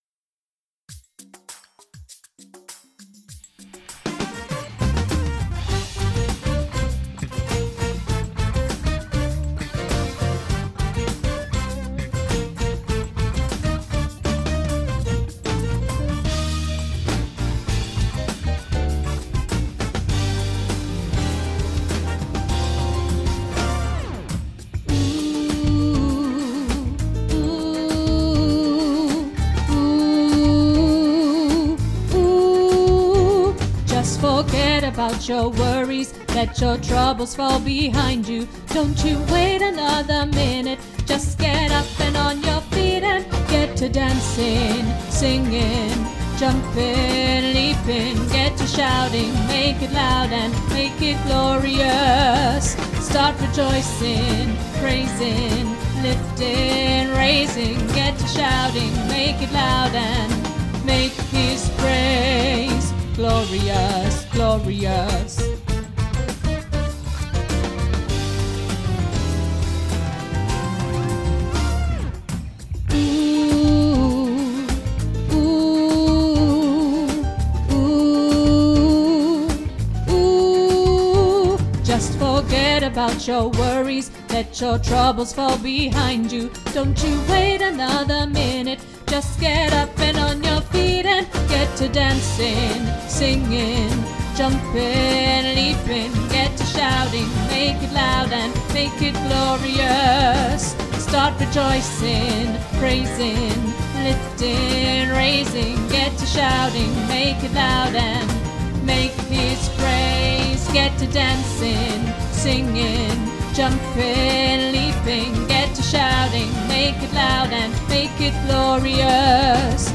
Glorious-Tenor.mp3